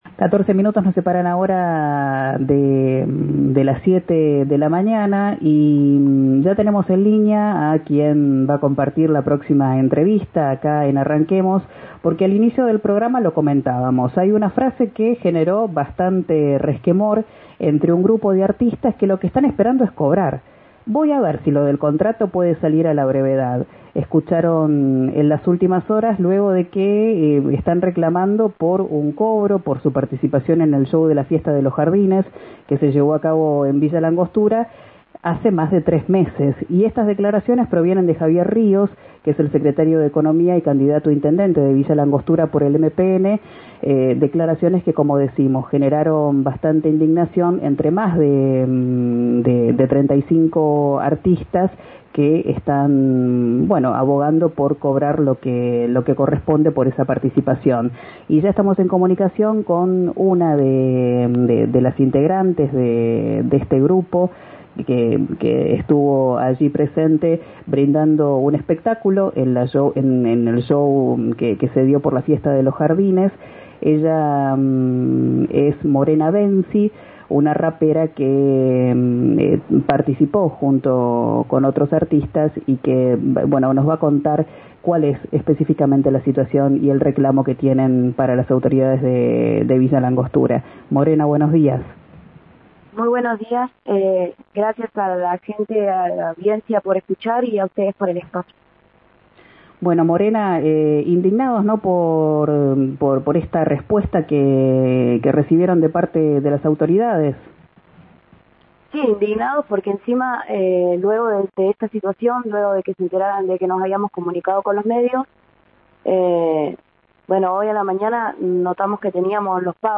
En comunicación con RADIO RIO NEGRO